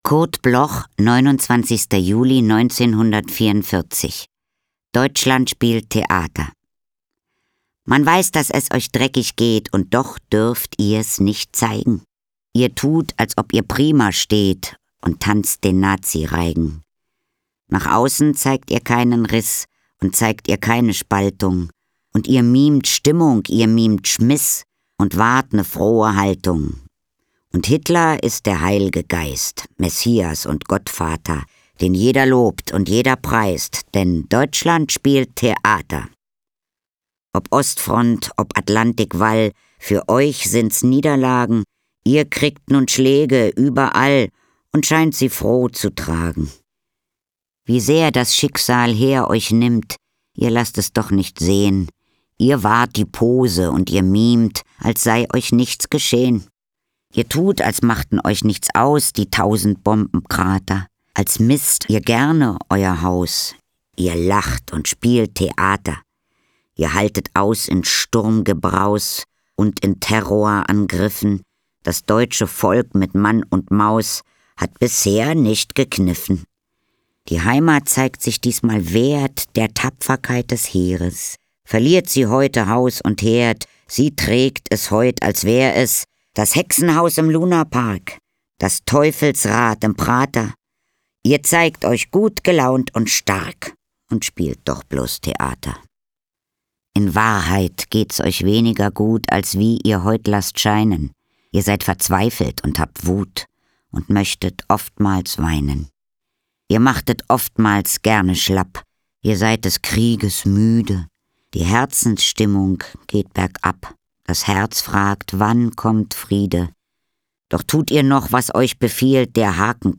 Katharina Thalbach (* 1954) is een Duitse actrice, regisseuse, luisterboek-, hoorspel- en nasynchronisatieactrice.